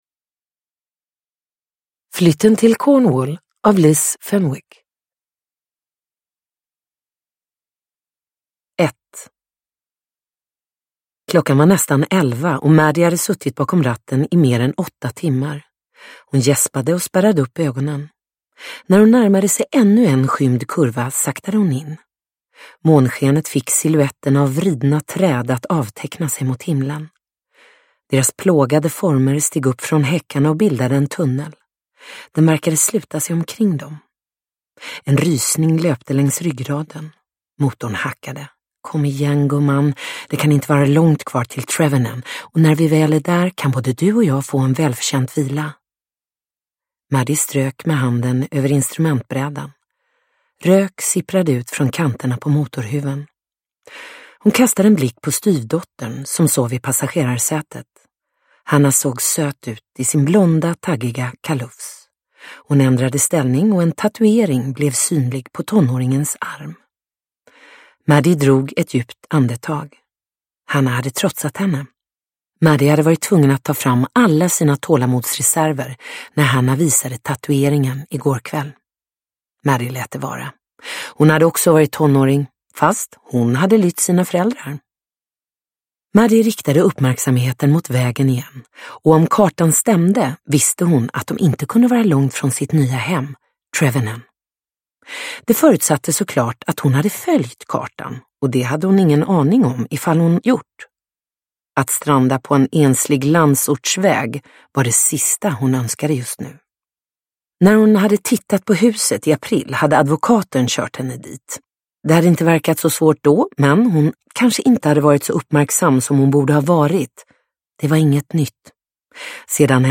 Flytten till Cornwall – Ljudbok – Laddas ner